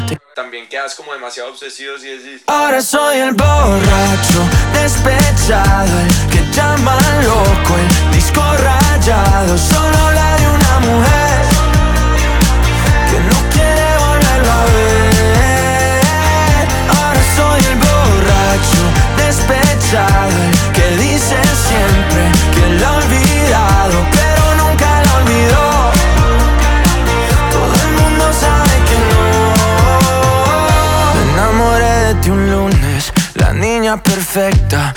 Скачать припев
Pop Latino Latin